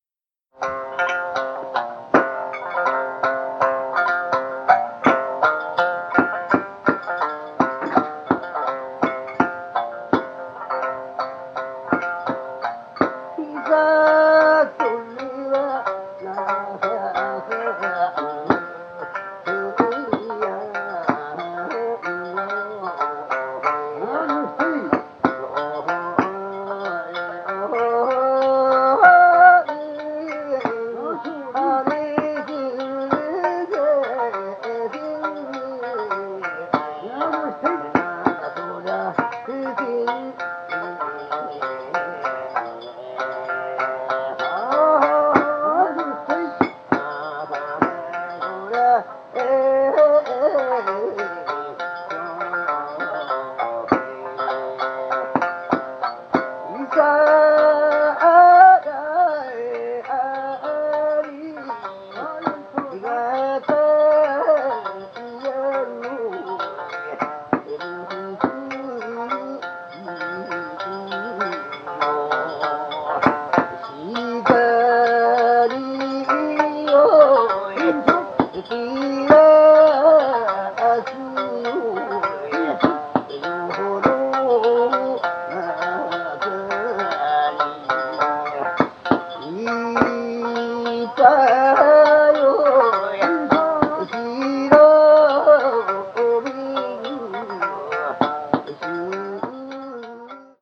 Osharaku is a traditional Japanese form of musical entertainment, featuring mainly voice, shamisen and percussion, with a repertoire of folk and popular songs, performed by farmers and fishermen for local audiences.
These recordings feature legendary Osharaku masters, recorded in their homes, brilliantly capturing the casual warmth and vitality characteristic of the style.
These joyous tracks, all recorded in the 1960s and 70s, are available as a 2CD set or a single 12” vinyl LP, with English translations and rare photos.
The Kasai Osharaku Preservation Society and others